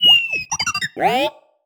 sci-fi_driod_robot_emote_02.wav